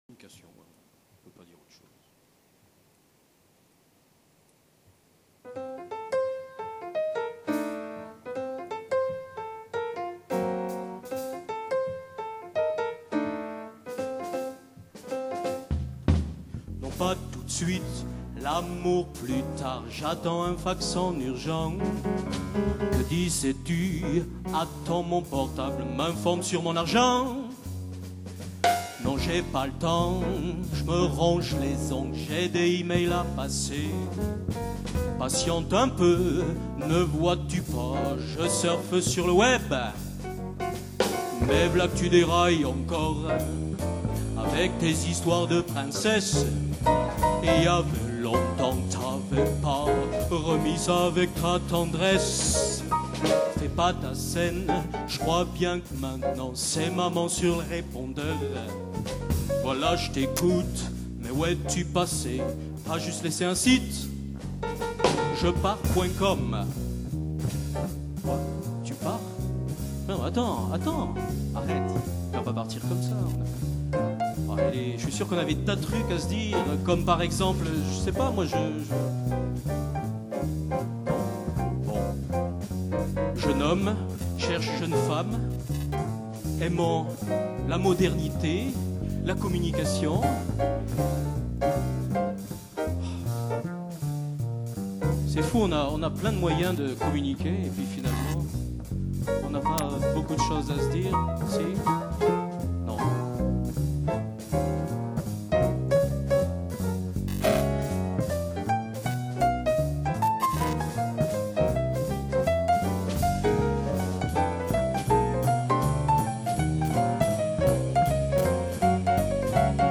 compositeur, chanteur, guitariste
en duo guitare contrebasse